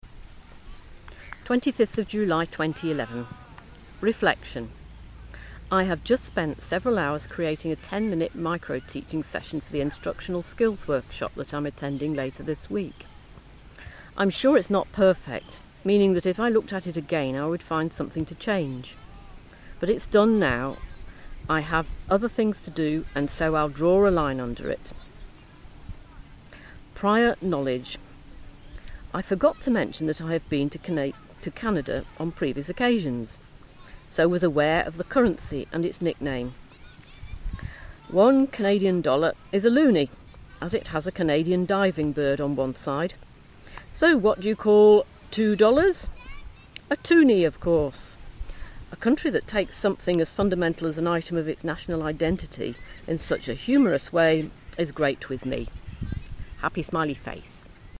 Reflection, prior knowledge (outside broadcast)